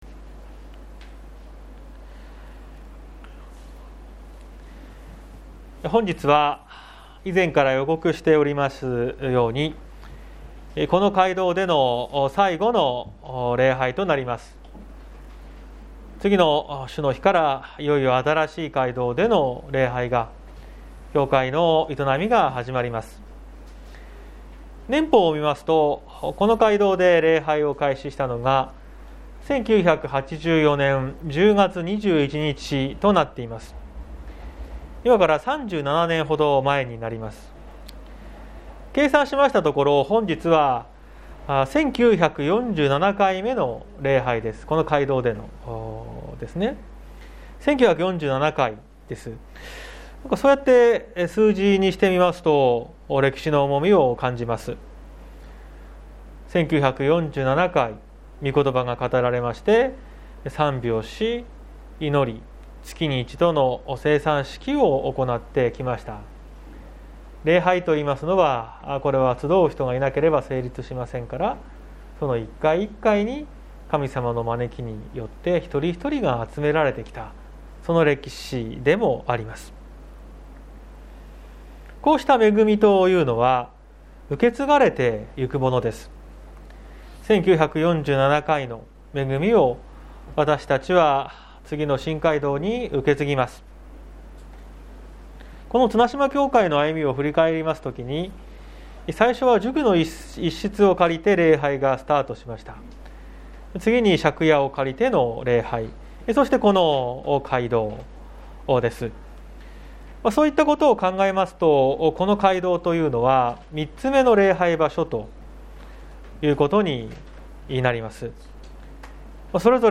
2022年02月06日朝の礼拝「教会に結ばれて」綱島教会
説教アーカイブ。